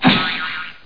00626_Sound_Poof.mp3